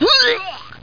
hurl1.mp3